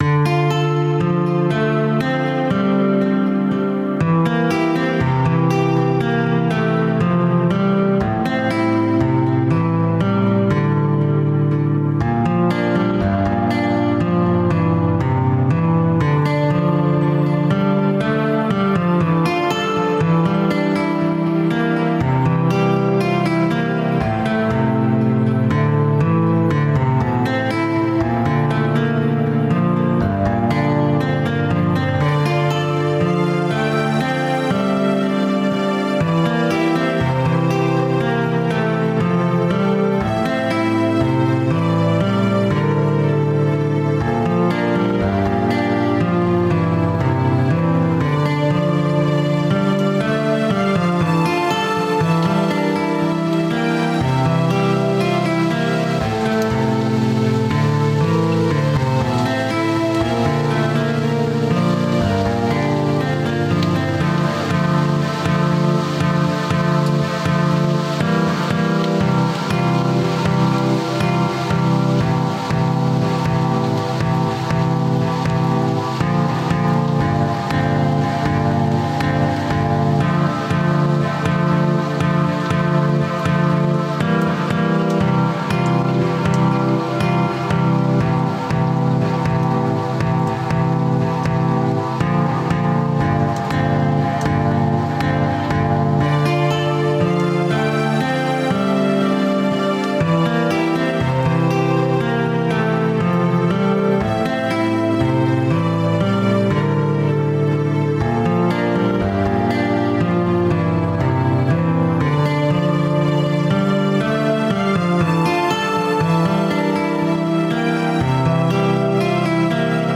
Looking Strange to Myself - Electronic - Young Composers Music Forum
Dark wave, is one of my favorite genres in "modern" music.